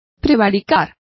Complete with pronunciation of the translation of prevaricates.